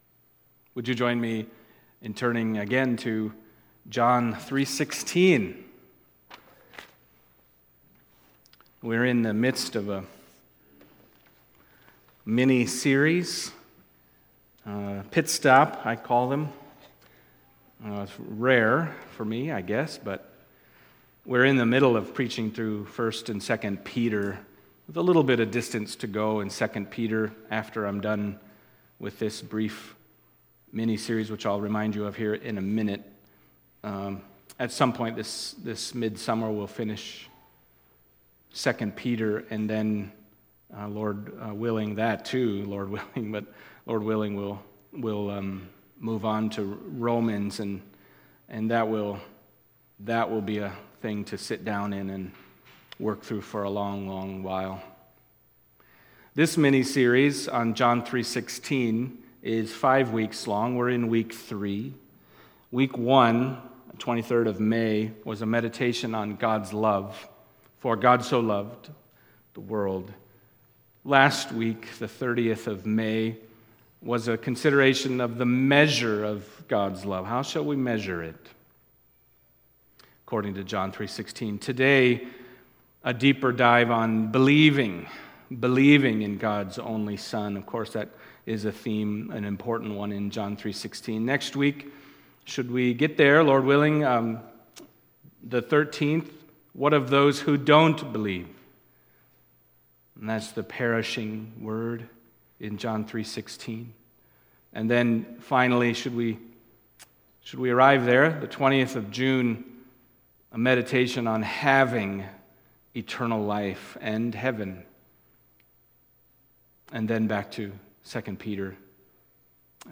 John 3:16-21 Service Type: Sunday Morning John 3:16 « The Measure of God’s Love What of Those Who Don’t Believe?